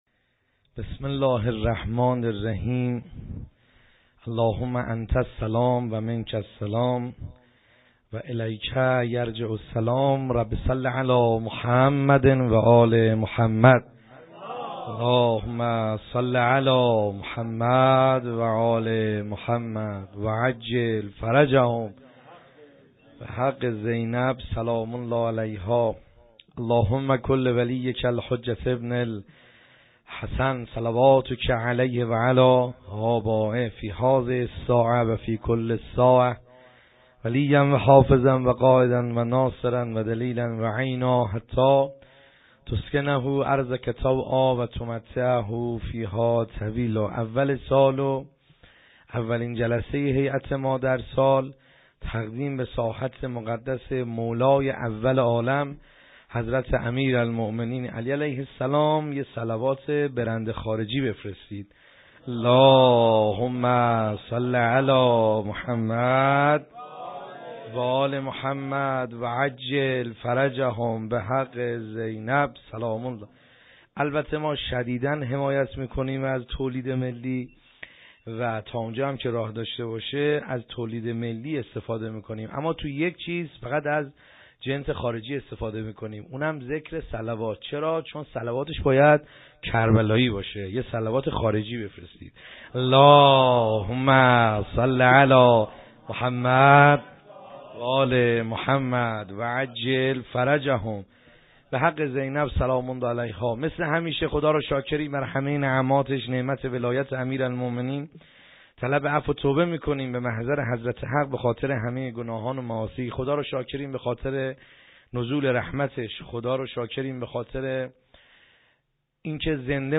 خیمه گاه - بیرق معظم محبین حضرت صاحب الزمان(عج) - سخنرانی | کیفیت پایین